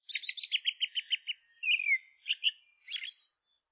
PAJARO EN UN PARQUE
Tonos EFECTO DE SONIDO DE AMBIENTE de PAJARO EN UN PARQUE
Pajaro_en_un_parque.mp3